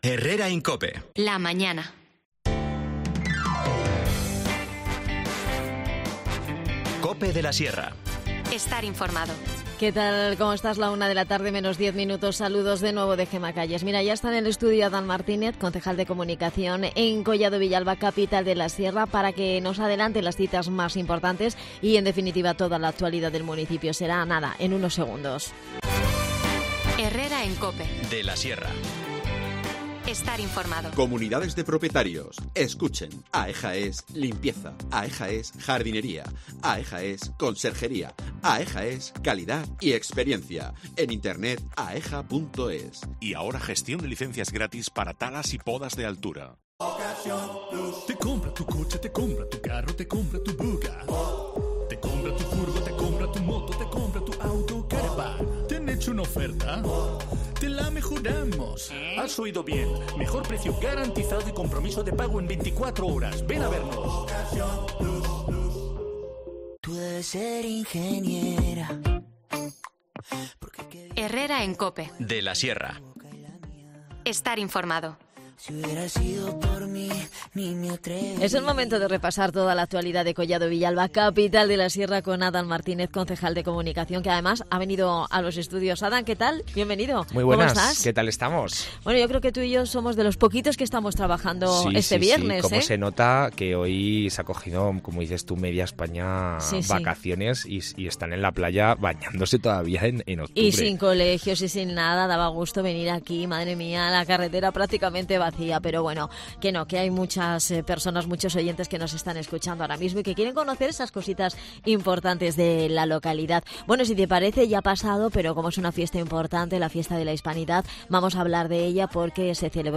De estas y otras iniciativas hemos hablado con Adan Martínez, concejal de Comunicación en Collado Villalba, Capital de la Sierra.
Las desconexiones locales son espacios de 10 minutos de duración que se emiten en COPE, de lunes a viernes.